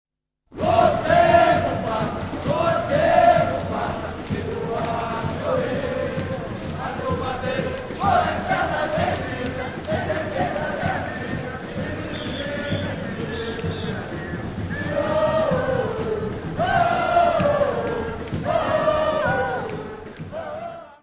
Carnavals du Bresil : Rio, Recife, Bahia : live recording = Carnivals of Brazil
Área:  Tradições Nacionais
Ecole de Samba - Batucada & Chant - Rio.